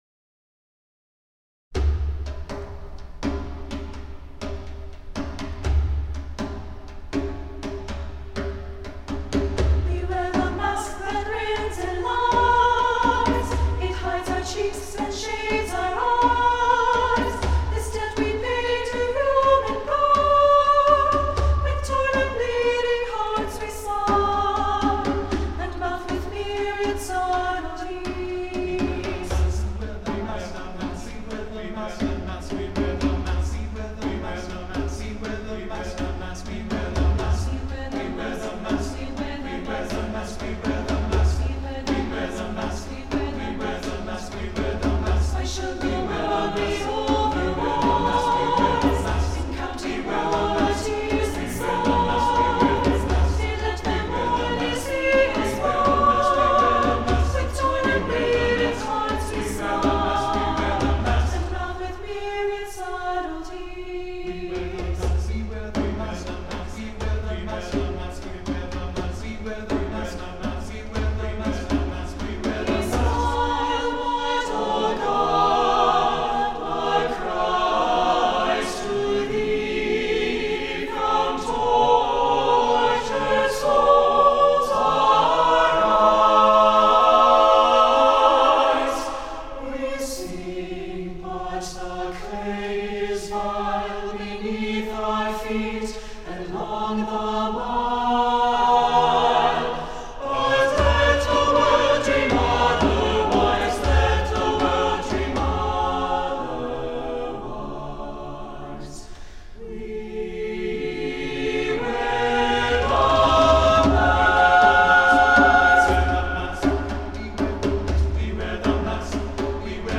Voicing: SATB and Djembe